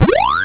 gs_eatghost.au